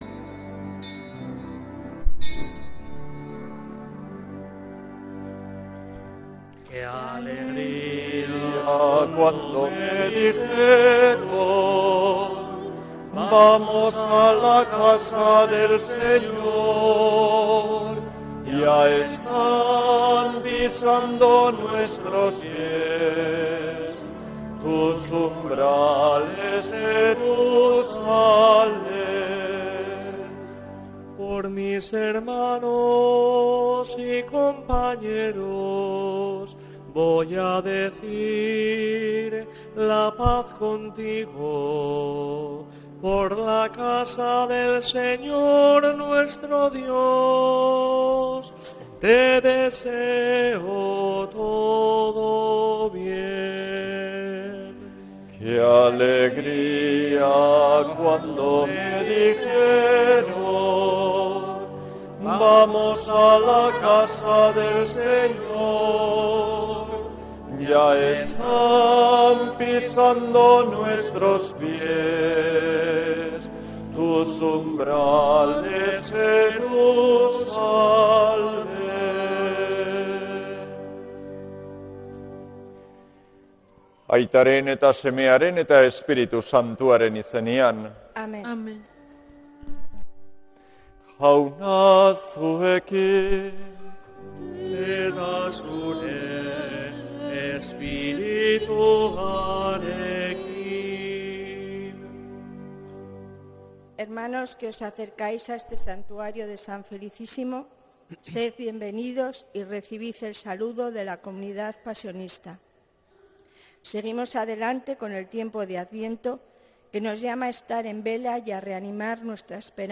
Santa Misa desde San Felicísimo en Deusto, domingo 14 de diciembre de 2025